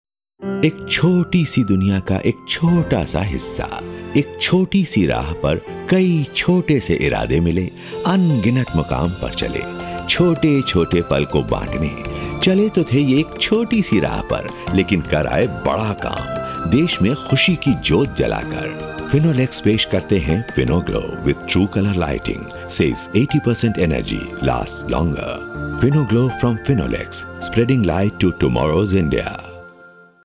My voice ranges from middle aged to senior.
Deep, warm, soft, soothing, smooth, voice.
Sprechprobe: Industrie (Muttersprache):